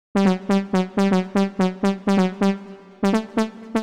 XTRA057_VOCAL_125_A_SC3.wav